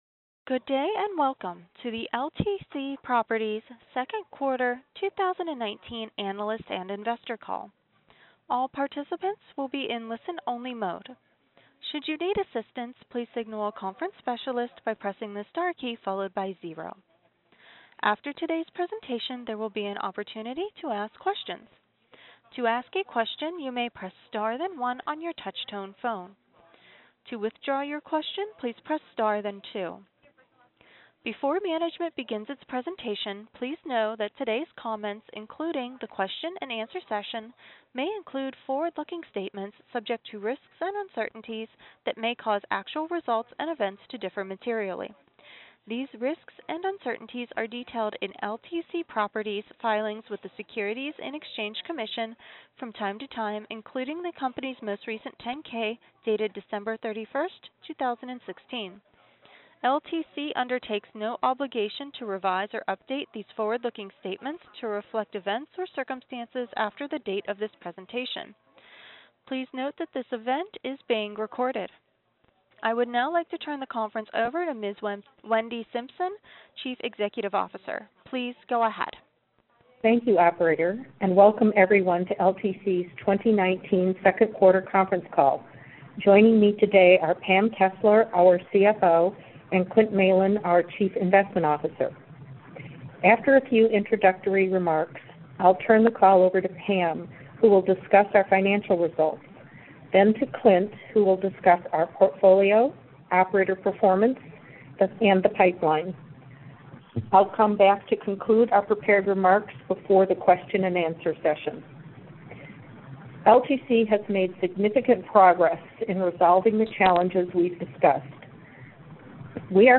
Audio-Replay-of-LTC-Properties-Inc-Q2-2019-Earnings-Call.mp3